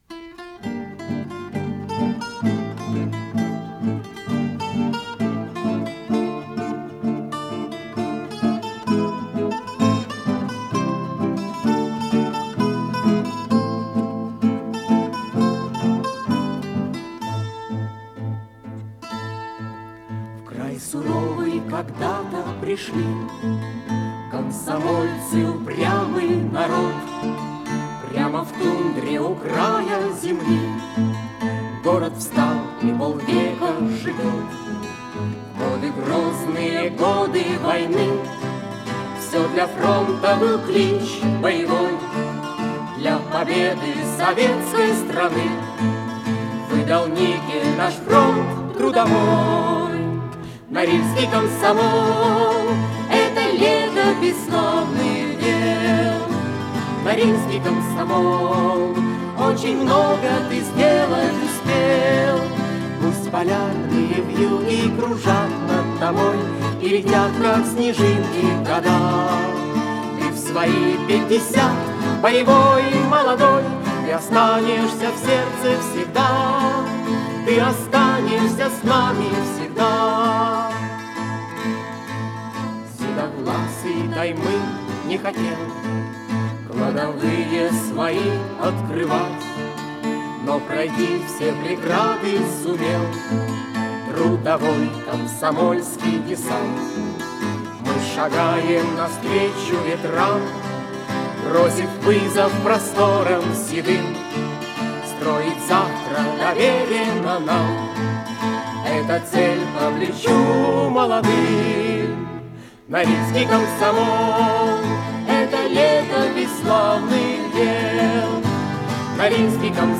гитара, пение
Аккомпанимент
ВариантДубль моно